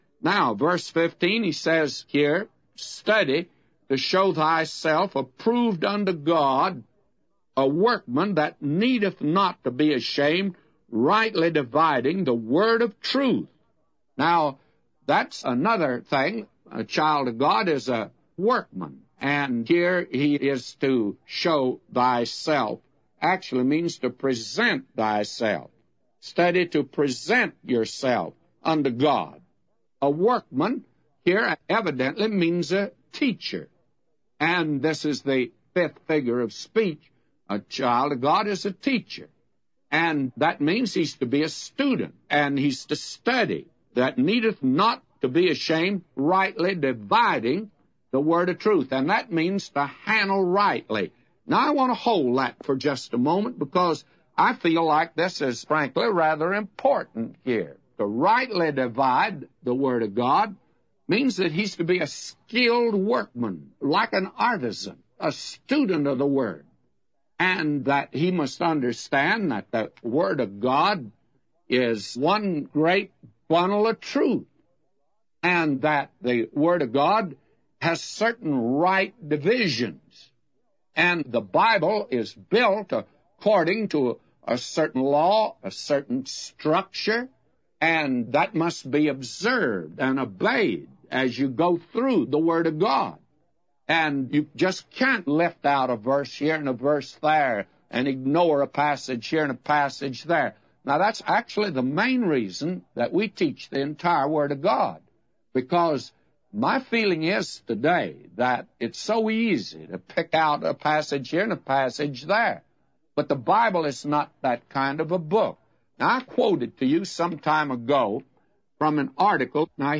Click here for short but excellent audio teaching by Dr. J. Vernon McGee on 2 Timothy 2:15 “Study to shew thyself approved unto God, a workman that needeth not to be ashamed, rightly dividing the word of truth.”